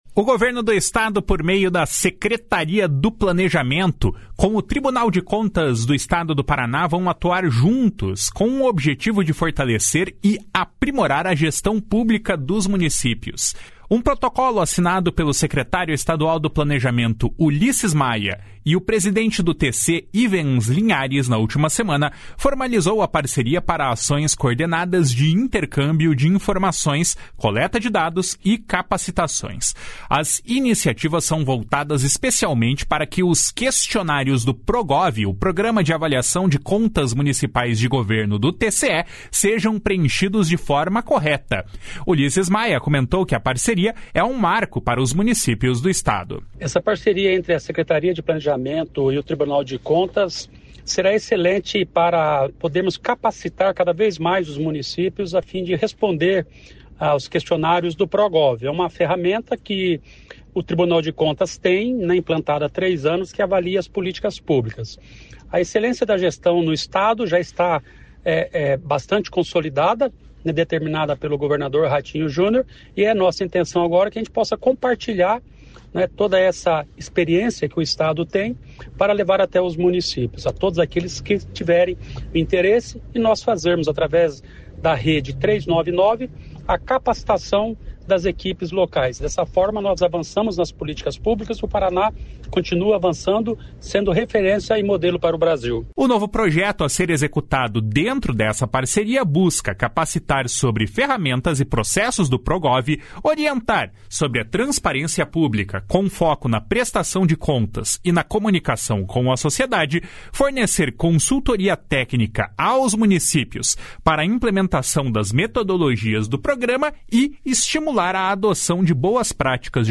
Ulisses Maia comentou que a parceria é um marco para os municípios do Estado. // SONORA ULISSES MAIA //